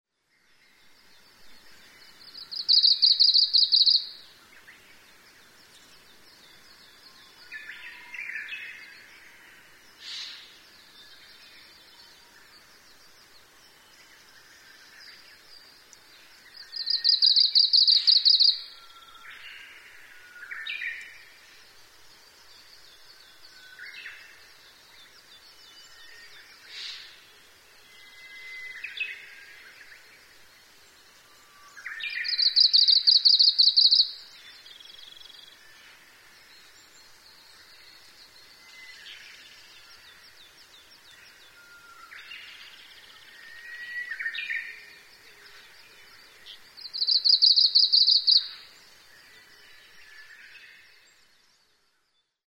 oomushikui_s1.mp3